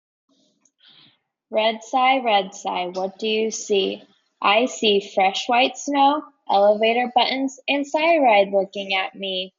A repetitive, rhythmic chant follows Red Cy as it sees fresh white snow, elevator buttons, and Cy Ride, building to a final cumulative line.